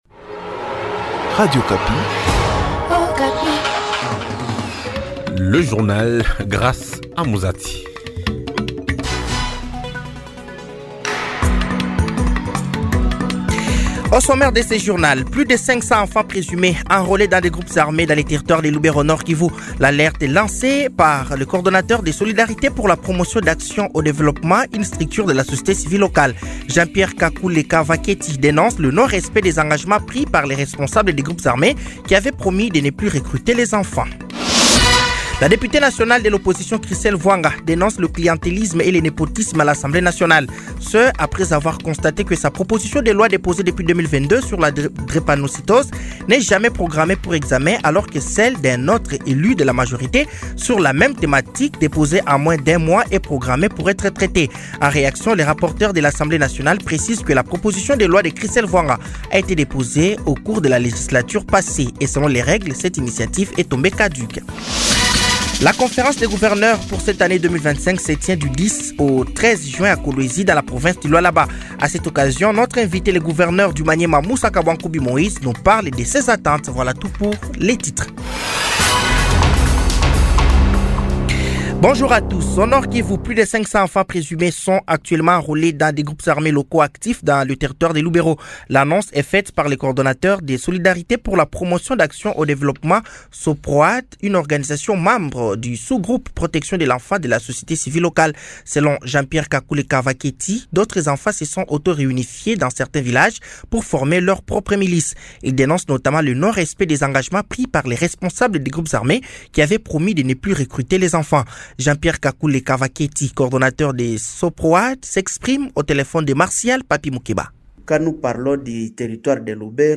Journal français de 15h de ce dimanche 08 juin 2025